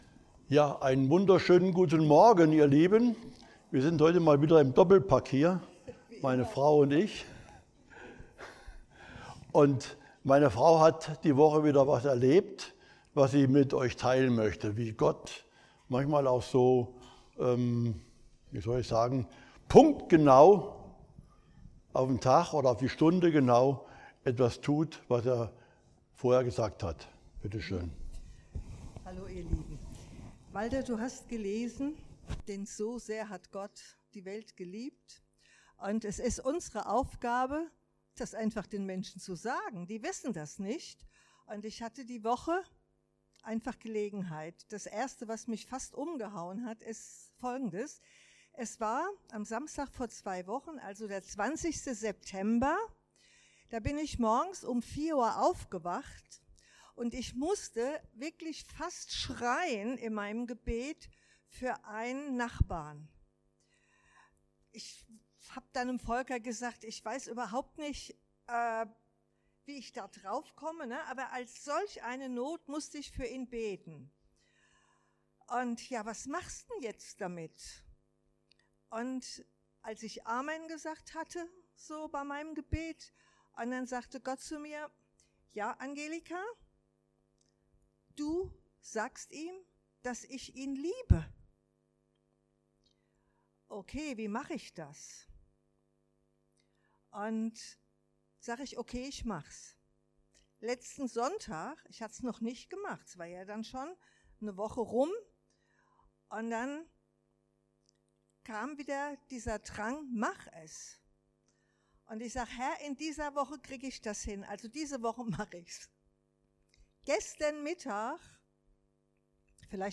Apg.2,37-39 Dienstart: Predigt Der Heilige Geist gibt Leben und Wachstum.